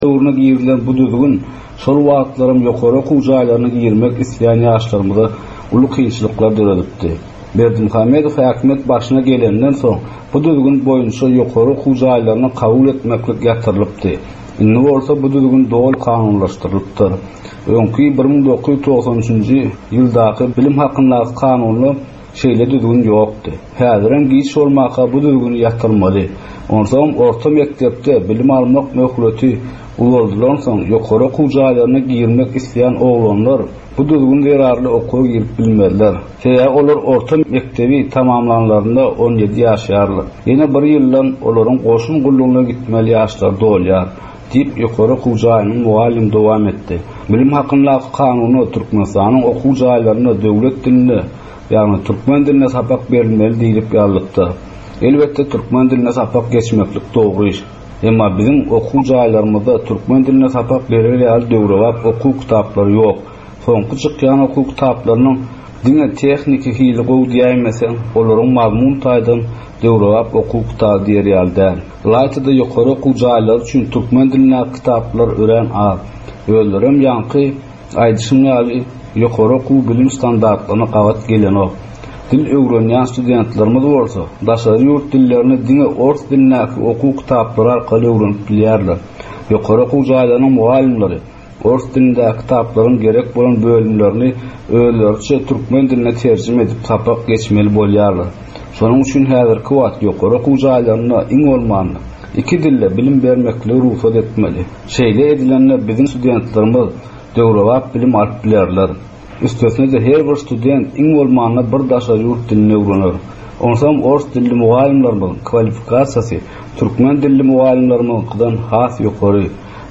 Türkmen we halkara yaslarynyn durmusyna degisli derwaýys meselelere we täzeliklere bagyslanylyp taýýarlanylýan 15 minutlyk ýörite geplesik. Bu geplesiklde ýaslaryn durmusyna degisli dürli täzelikler we derwaýys meseleler barada maglumatlar, synlar, bu meseleler boýunça adaty ýaslaryn, synçylaryn we bilermenlerin pikrileri, teklipleri we diskussiýalary berilýär. Geplesigin dowmynda aýdym-sazlar hem esitdirilýär.